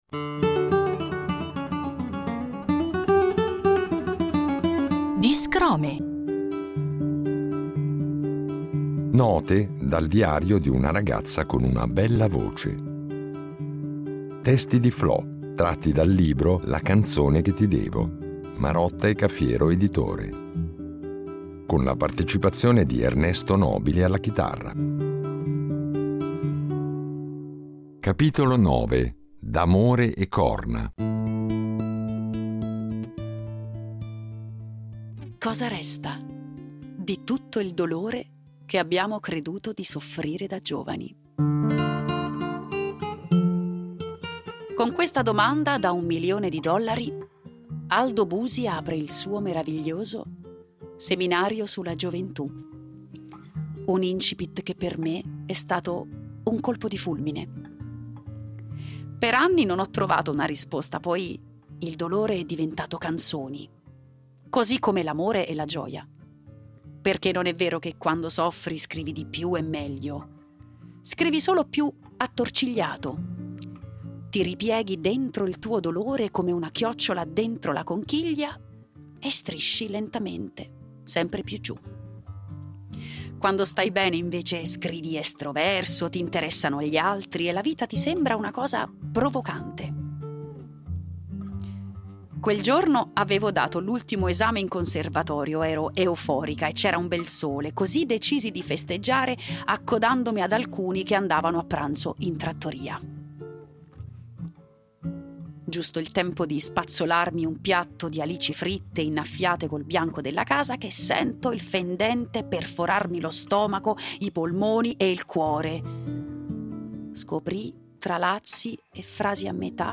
chitarrista